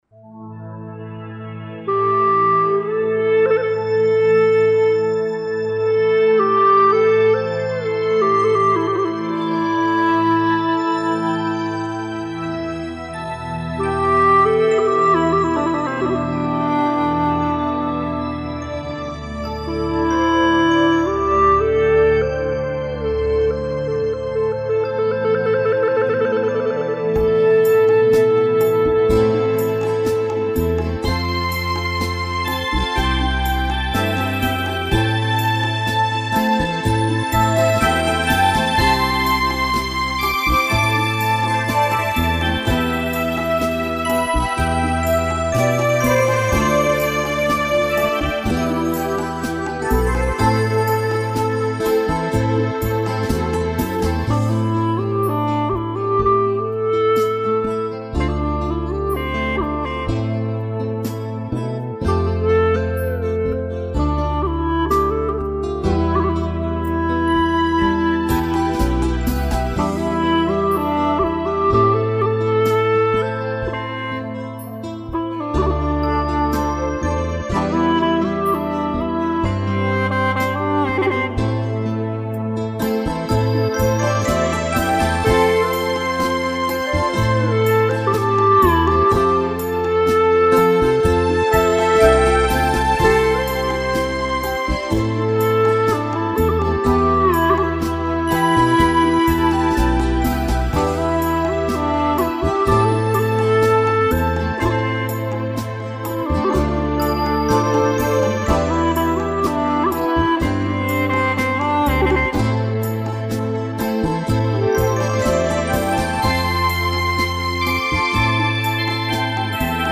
调式 : F=1